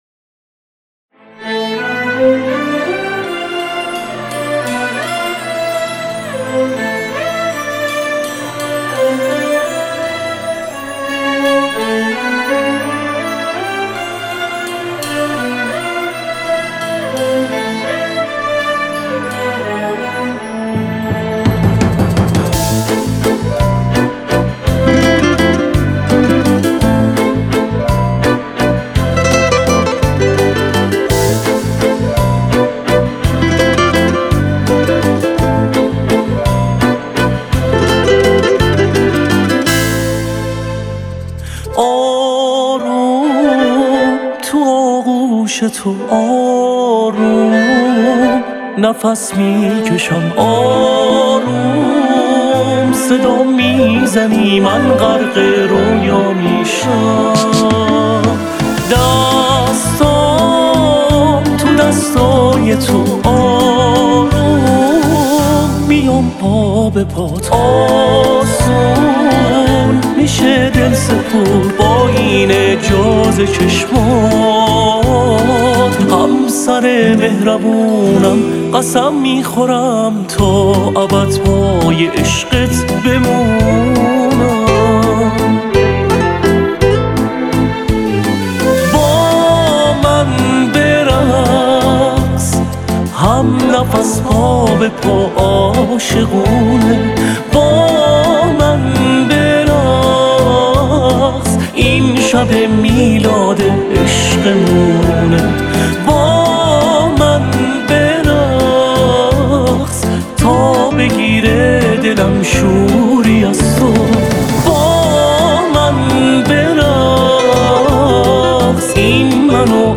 گیتار ……………………………………………………………………………………….
ویولن …………………………………………………………………………………………